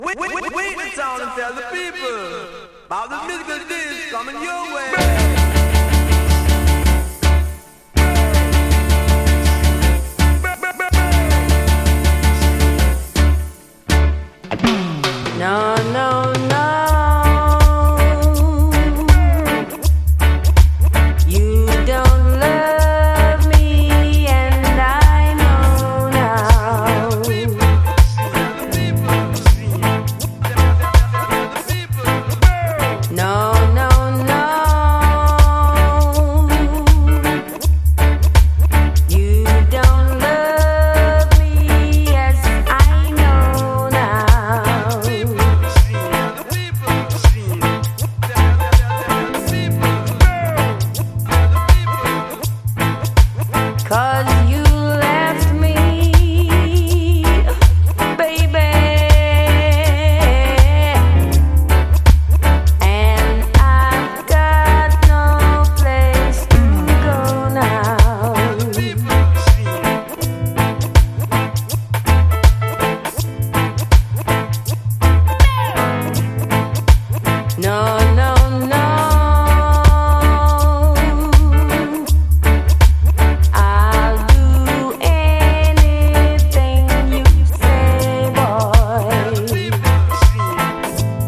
レゲエやダンスホール界のみならず、ヒップホップの現場でもプライされまくったビッグ・チューン。